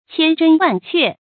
千真萬確 注音： ㄑㄧㄢ ㄓㄣ ㄨㄢˋ ㄑㄩㄝˋ 讀音讀法： 意思解釋： 真：真實；確：確實。